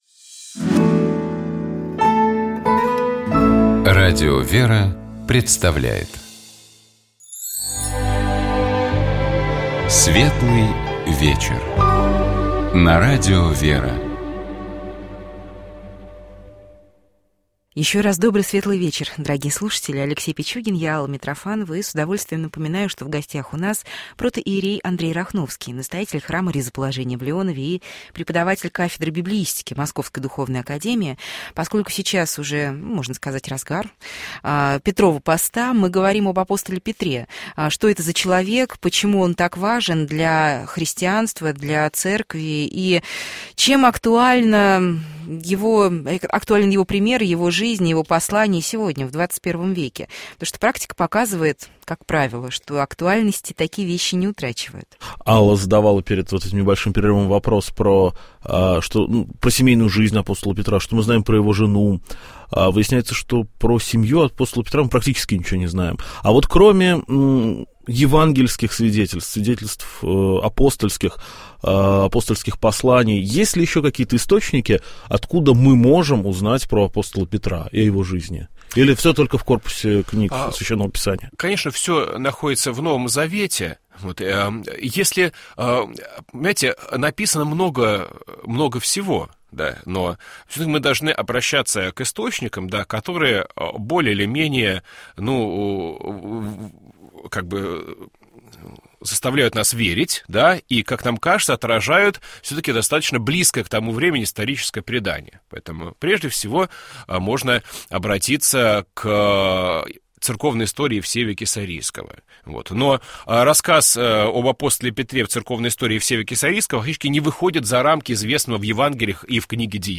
В начале Петровского поста мы говорили о его истории, а также об апостоле Петре, его жизни, служении и посланиях, вошедших в состав книг Нового Завета.